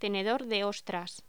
Locución: Tenedor de ostras
voz